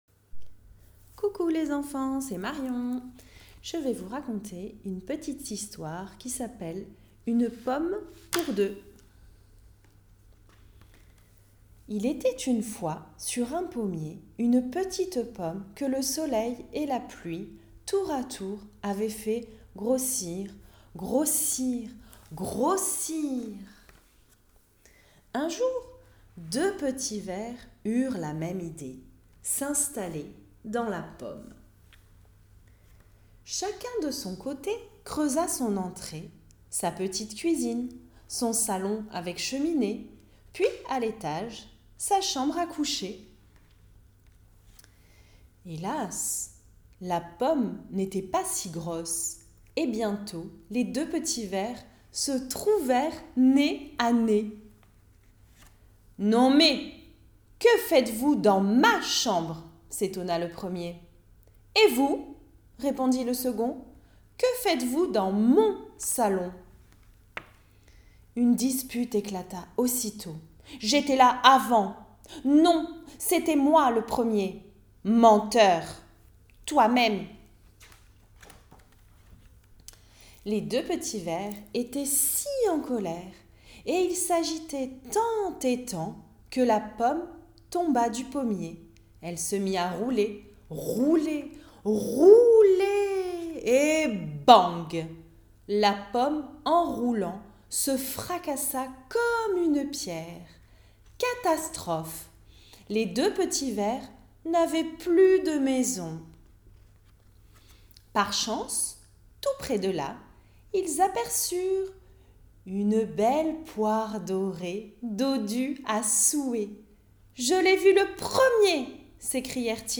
Une sélection de différents contes audio et histoires animées, vous sont proposés. Contés avec entrain et dynamisme